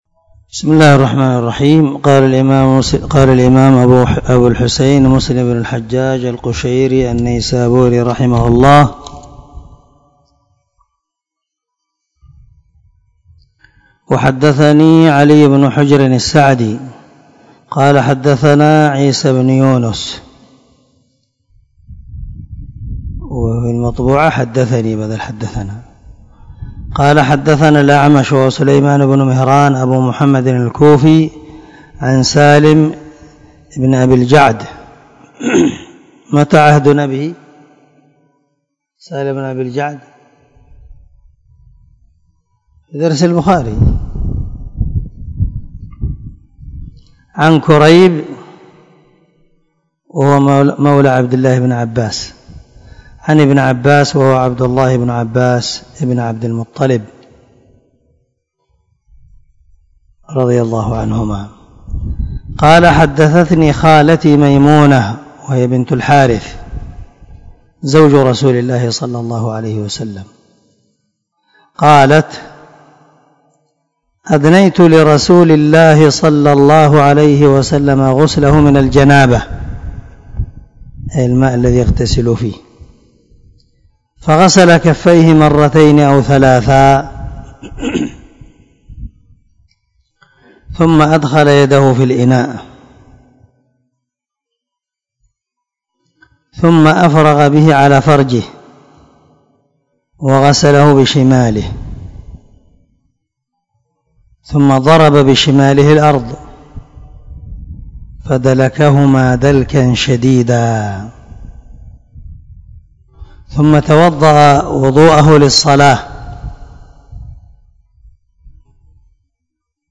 230الدرس 14 من شرح كتاب الحيض حديث رقم ( 317 - 318 ) من صحيح مسلم